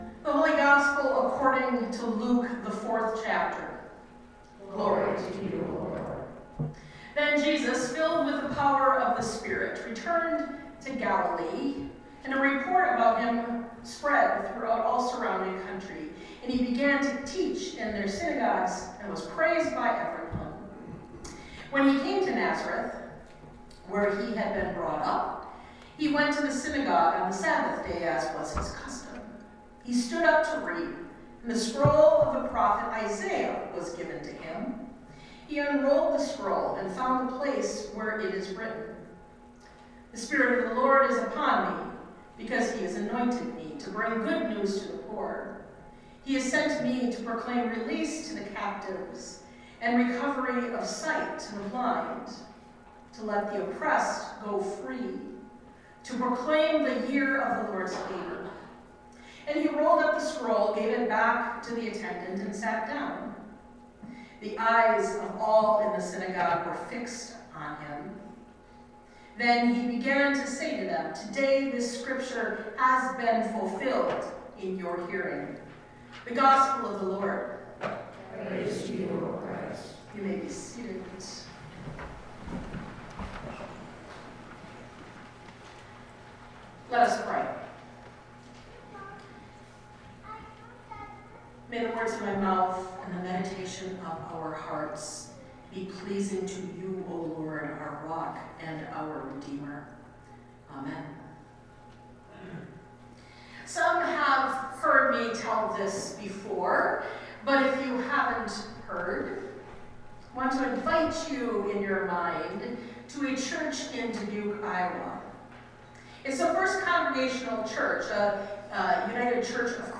Sermons by United Lutheran Church